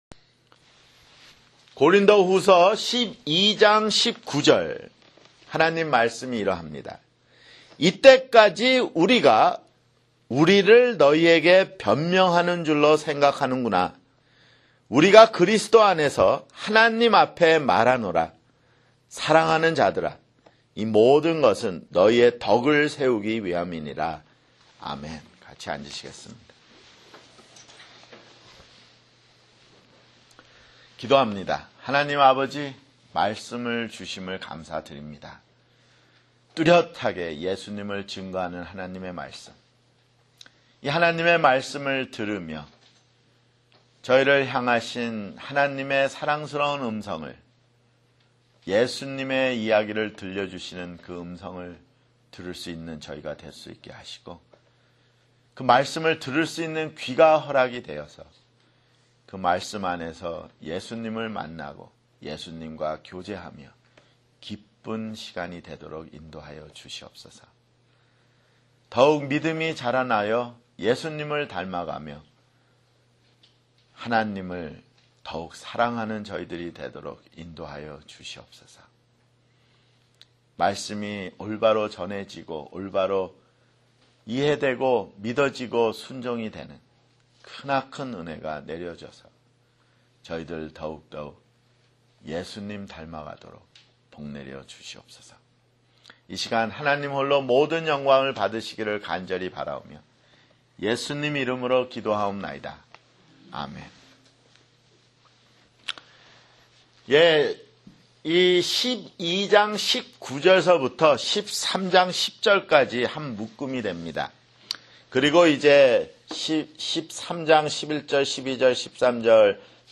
[주일설교] 고린도후서 (57)